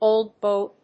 óld bóy
音節old boy発音記号・読み方‐́‐̀‐̀‐́